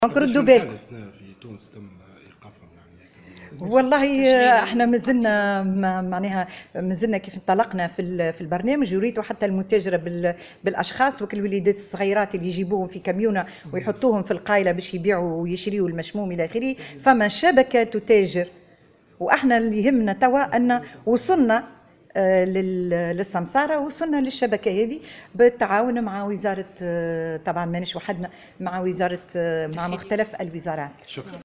وأعلنت في تصريح لمراسل "الجوهرة أف أم" على هامش ندوة في المهدية، أن وزارتها وبالتعاون مع مختلف الوزارات الأخرى توصّلت إلى الكشف عن شبكات سماسرة للاتجار بالأطفال واستخدامهم لبيع "المشموم" (الزهور) ويتم جلبهم مجموعات على متن شاحنات، مؤكدة أن العمل جار لتفكيك هذه الشبكات.